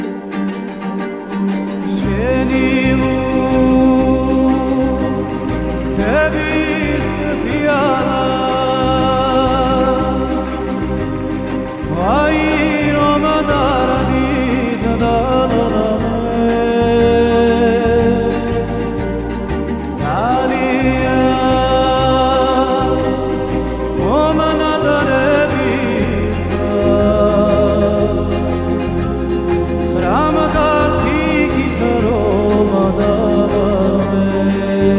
Жанр: Фолк-рок / Альтернатива
# Alternative Folk